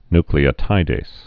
nu·cle·o·tid·ase
(nklē-ə-tīdās, -dāz, ny-)